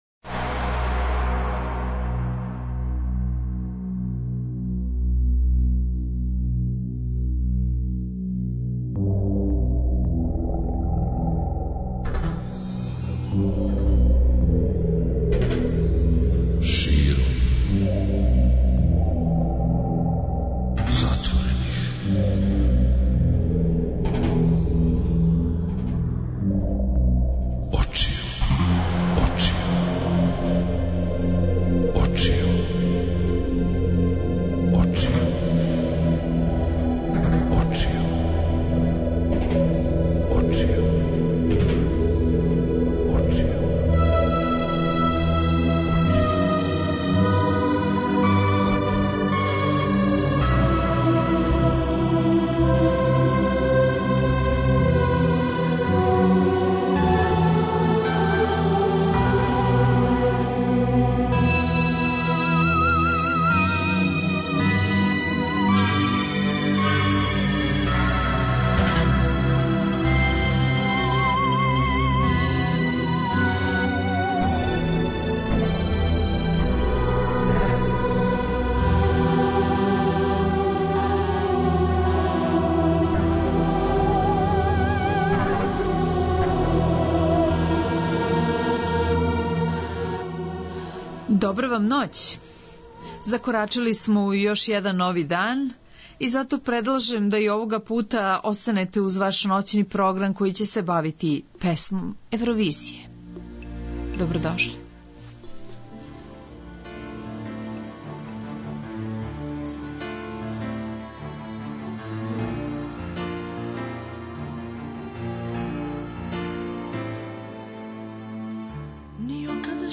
Слушаоци ће имати прилике да чују неке од такмичарских песама, али и неке од највећих хитова ове музичке смотре.